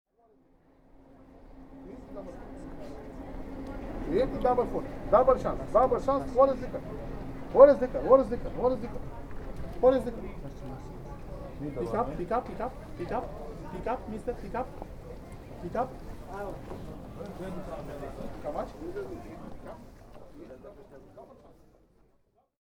Tags: London UK Beggar sounds Hustler sounds Hustlers and beggars Sounds